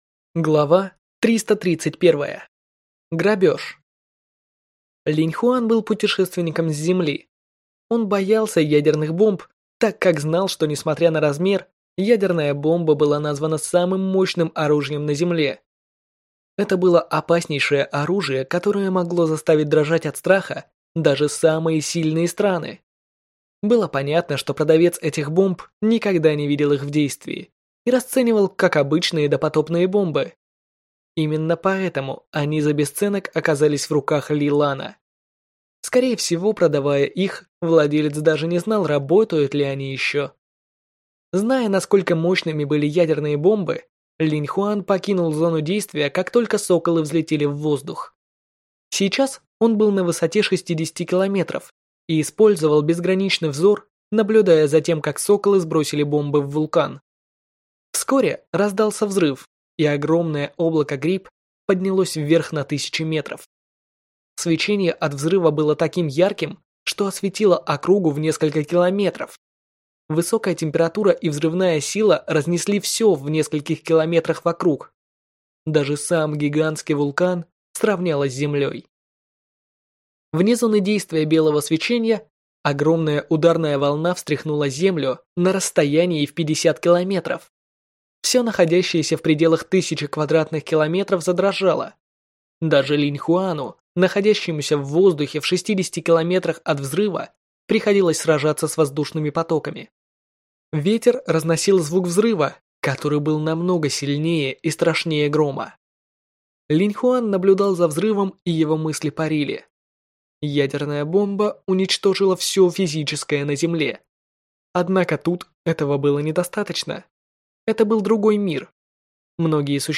Аудиокнига Рай монстров. Книга 2. Часть 3 | Библиотека аудиокниг